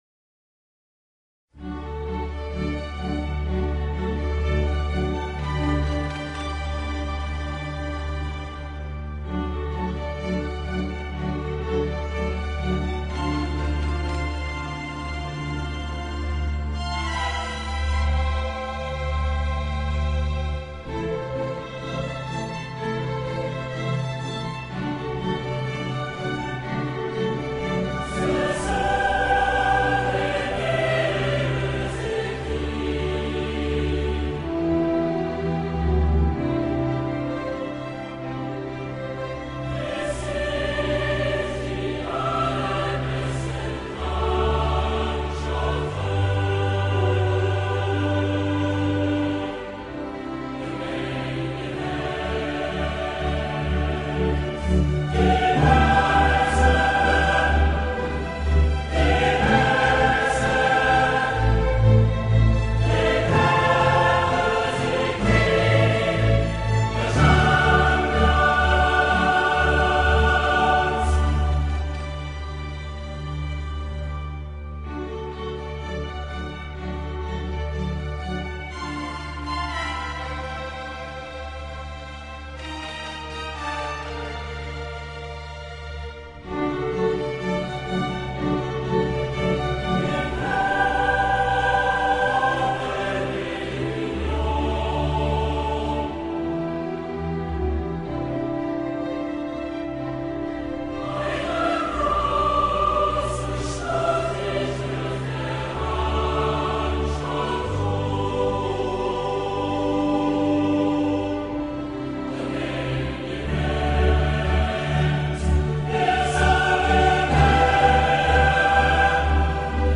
سرودی تا این اندازه شورانگیز و انرژی‌بخش